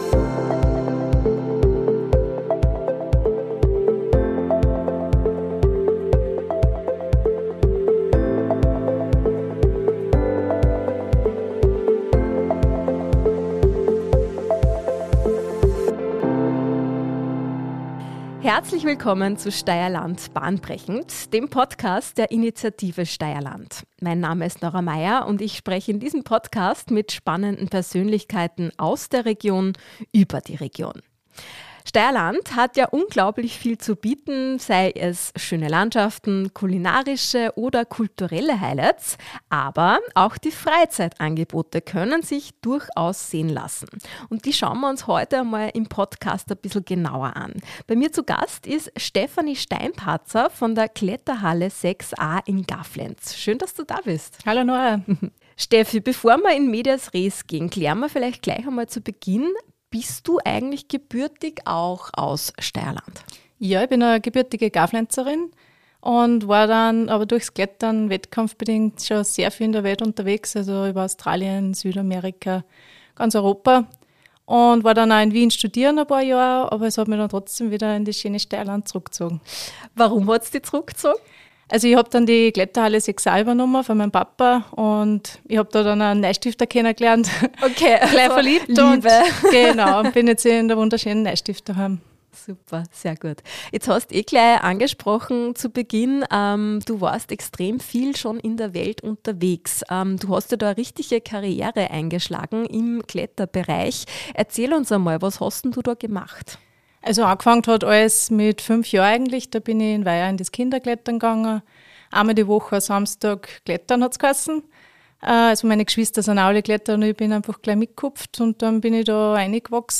im Gespräch mit Profi-Kletterin